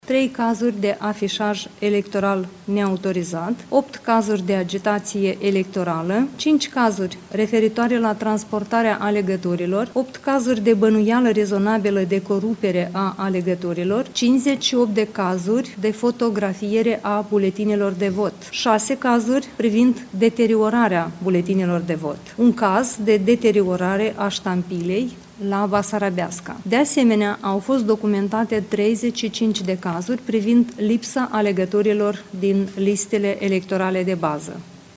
Cât despre incidentele de la secțiile de vot din țară, Angela Caraman a declarat la briefingul de presă că au fost confirmate peste 140 de cazuri în care a fost încălcată legislația.
Șefa Comisiei Electorală Centrală de la Chișinău, Angela Caraman: „Au fost documentate 35 de cazuri privind lipsa alegătorilor din listele electorale de bază”